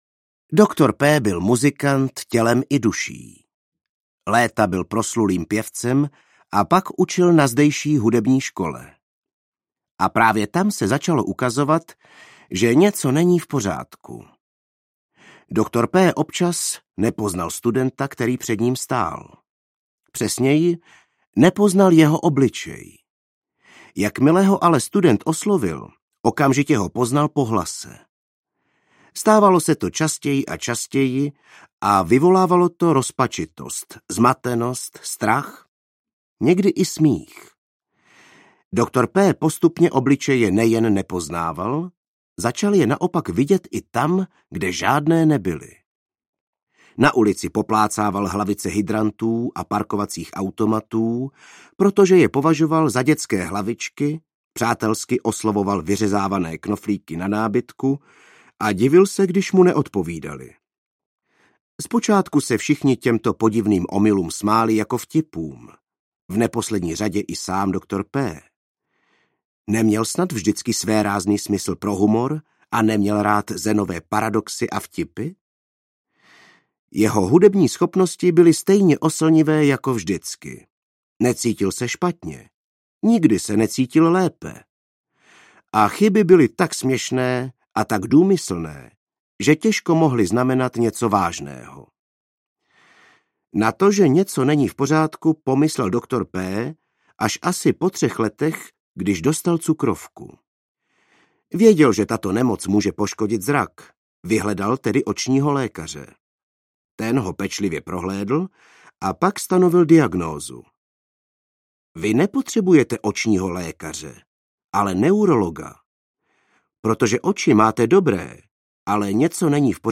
Muž, který si pletl manželku s kloboukem audiokniha
Ukázka z knihy
Vyrobilo studio Soundguru.
muz-ktery-si-pletl-manzelku-s-kloboukem-audiokniha